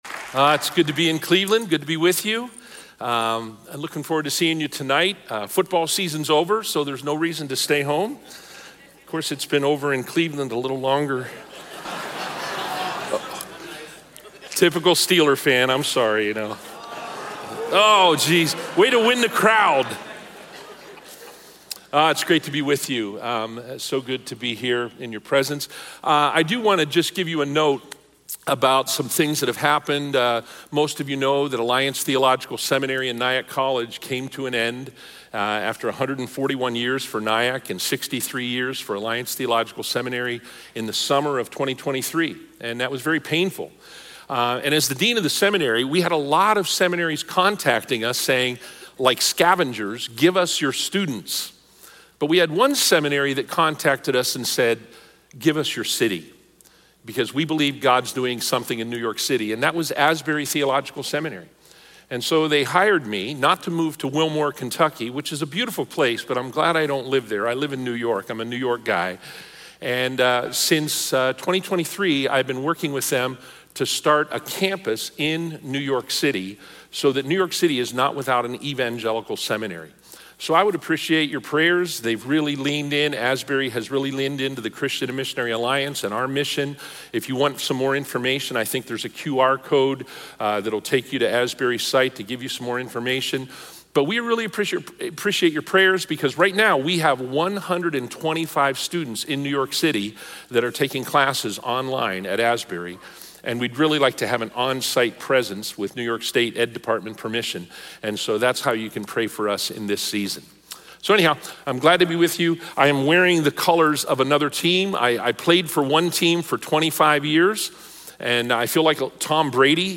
In today’s service, we focus on the powerful significance of recognizing and embracing divine encounters with Jesus and the Holy Spirit in our everyday lives. Through the story of the disciples on the road to Emmaus, we learn how easily we can miss moments where God is moving in our lives due to our emotional states, doubts, or distractions.